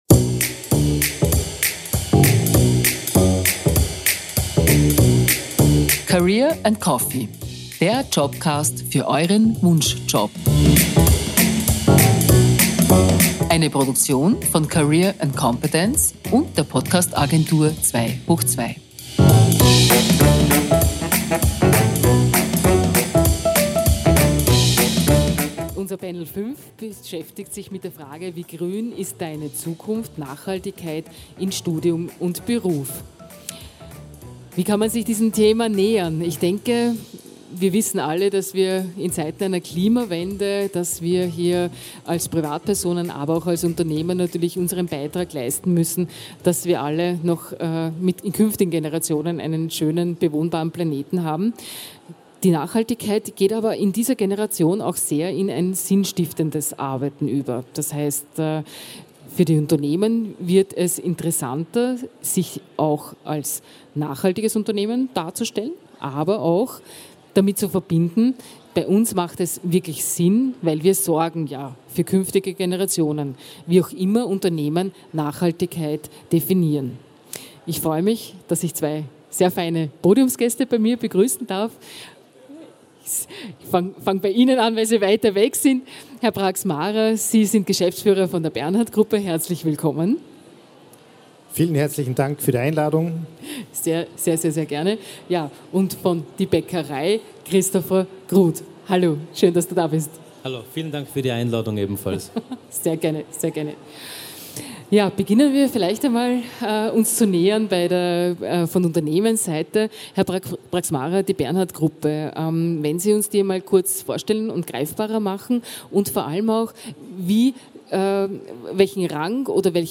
Experten aus zwei verschiedenen Bereichen diskutieren über Themen wie ökologische Verantwortung, nachhaltige Lösungen, ethisches Handeln und die Auswirkungen auf die eigene Karriere.
Livemitschnitt von der career & competence 2023 in Innsbruck, am 26. April 2023.